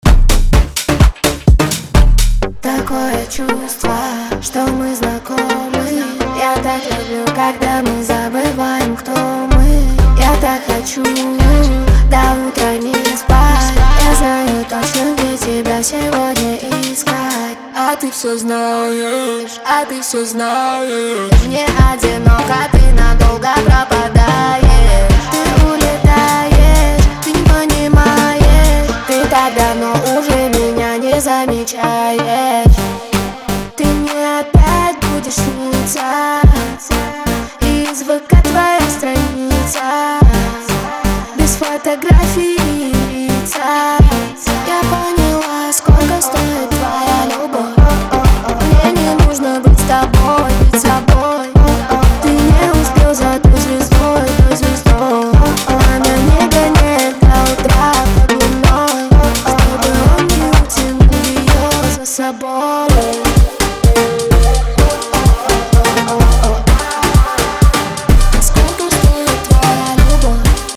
Рэп